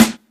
Wnd_Snr.wav